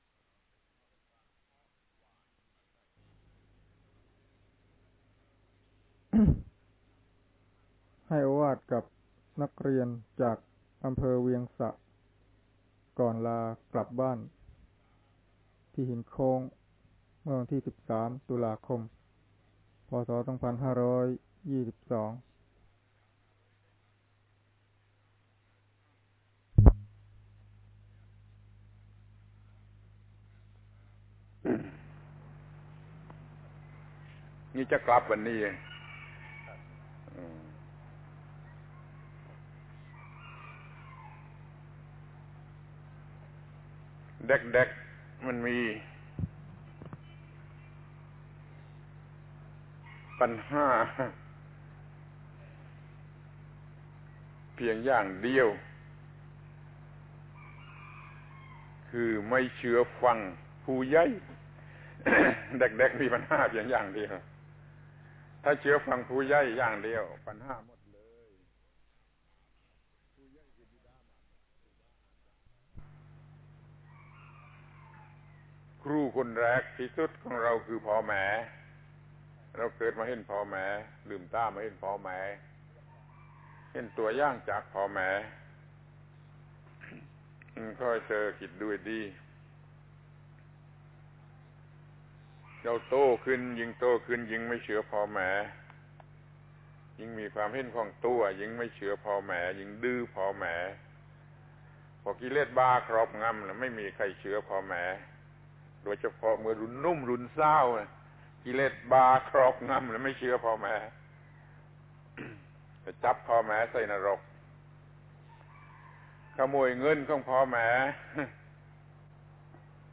พระธรรมโกศาจารย์ (พุทธทาสภิกขุ) - ให้โอวาทกับนักเรียนจากเวียงสระ ตอนลากลับ